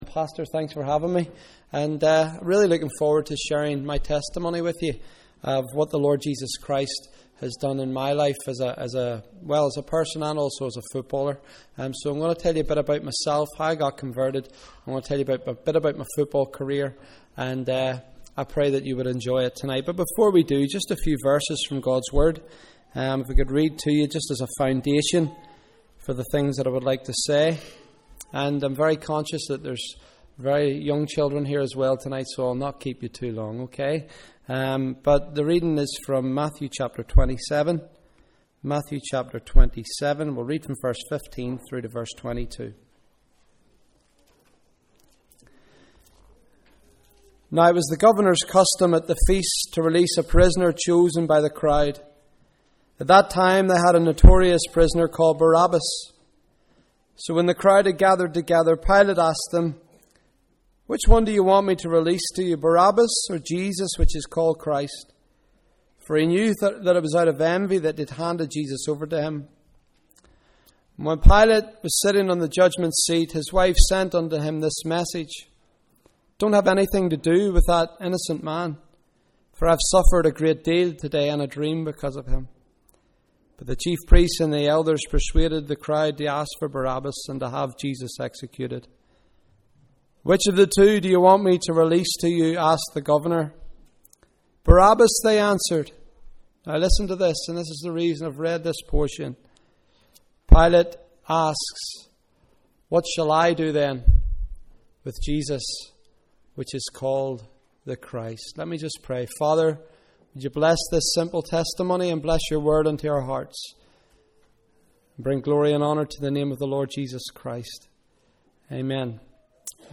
Bible Reading: Matthew 27 v 15 -22 Evening Service: Sunday 3rd November (BB/GB Enrolment)